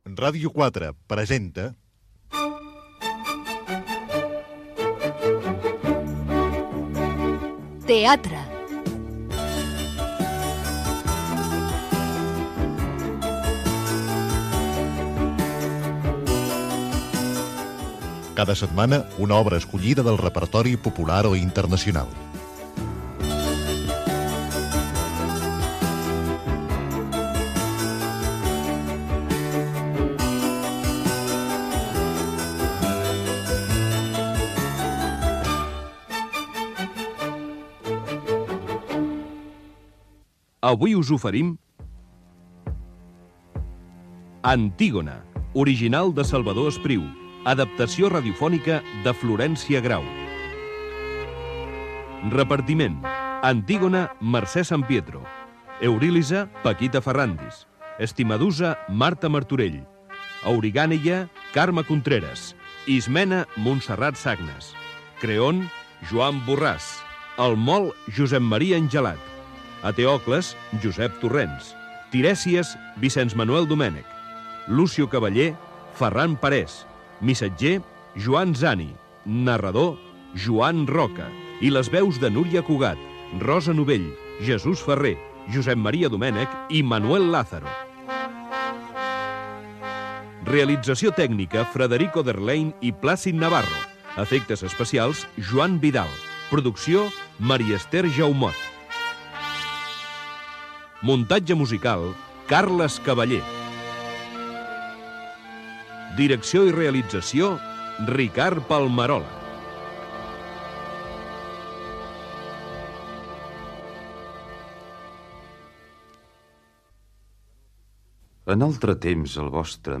Careta del programa
Diàleg de la primera escena de l'obra Gènere radiofònic Ficció